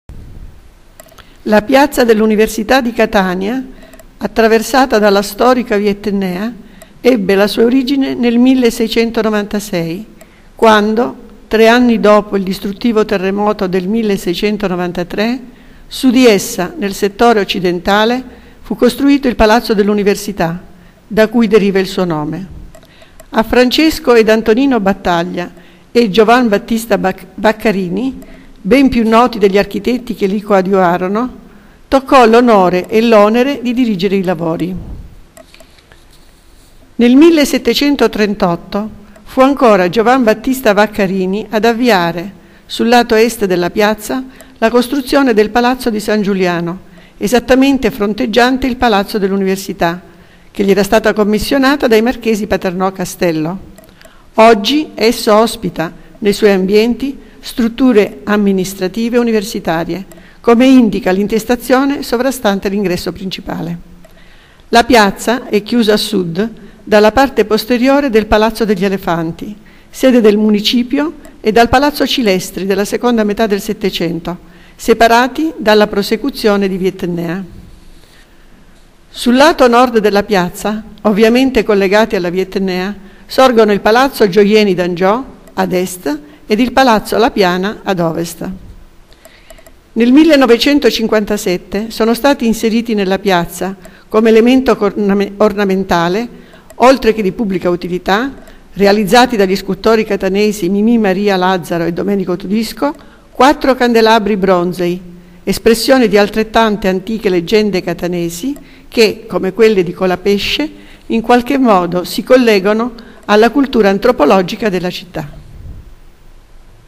Le audio guide di Ciceroni 3.0:Piazza Università